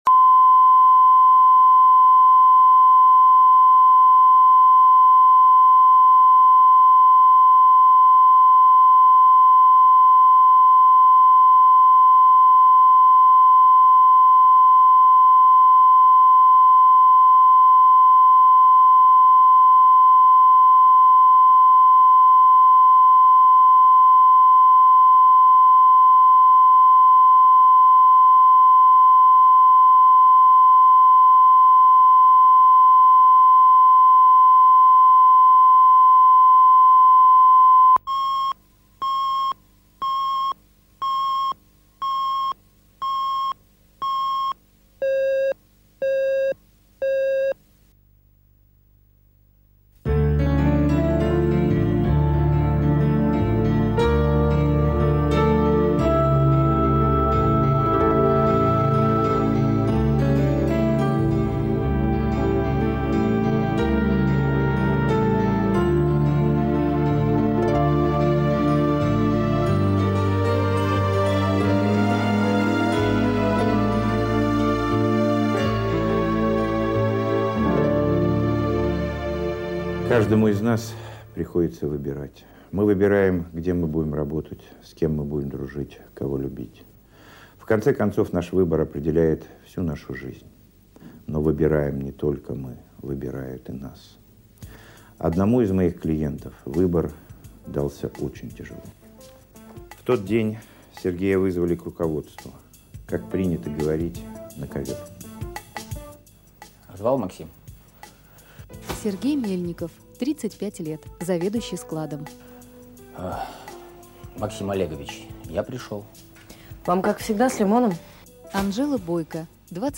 Aудиокнига Скользкая дорога Автор Александр Левин.